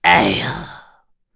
Bernheim-Vox_Sigh_kr_z.wav